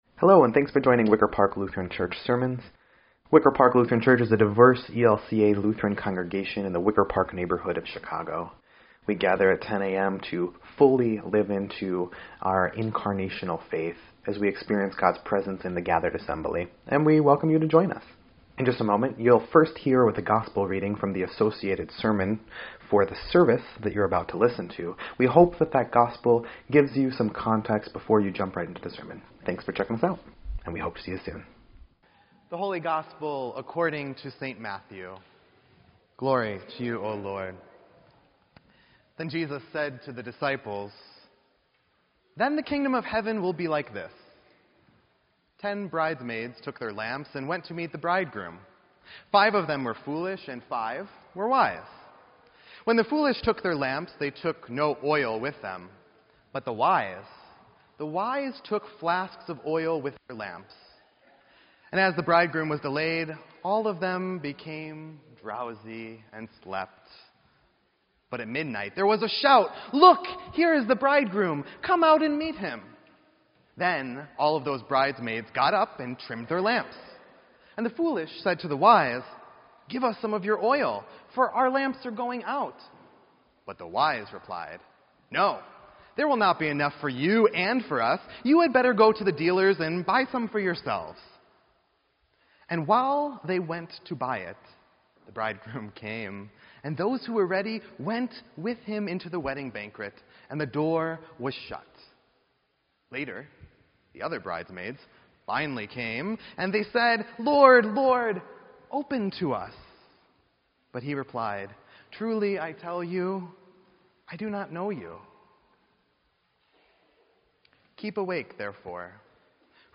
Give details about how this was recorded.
Wicker Park Lutheran Church